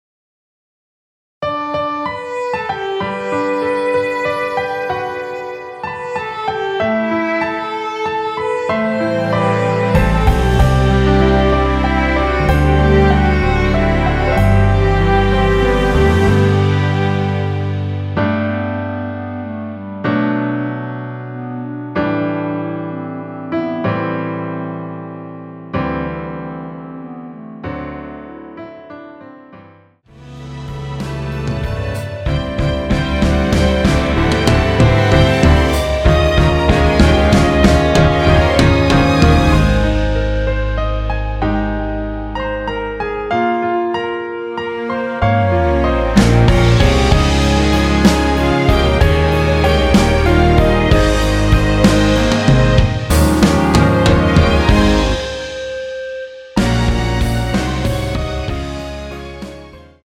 원키에서(-4)내린 멜로디 포함된 MR입니다.
앞부분30초, 뒷부분30초씩 편집해서 올려 드리고 있습니다.
(멜로디 MR)은 가이드 멜로디가 포함된 MR 입니다.